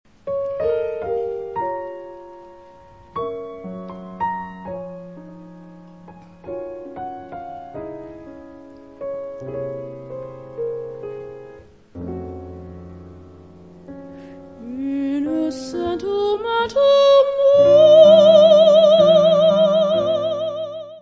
: stereo; 12 cm + folheto
piano
contrabaixo
bateria
Music Category/Genre:  Classical Music